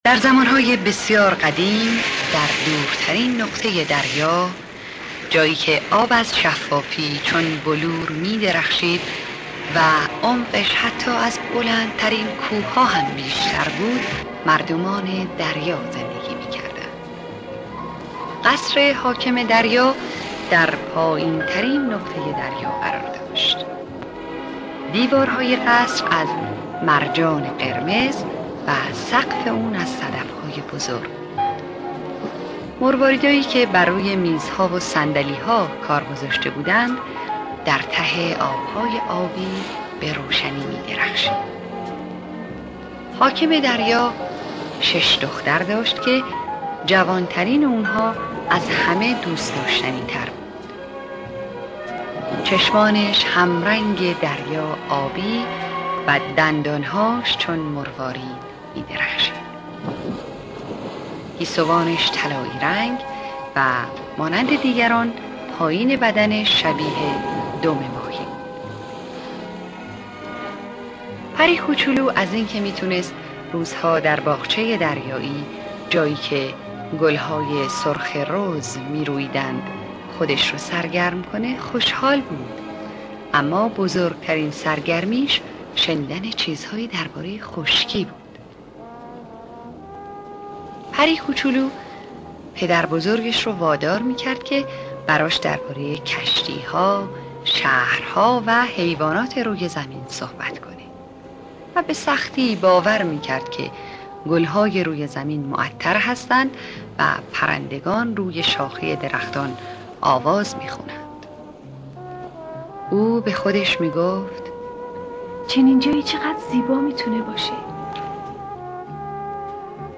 قصه کودکانه صوتی پری کوچولوی دریایی
قصه-کودکانه-صوتی-پری-کوچولوی-دریایی.mp3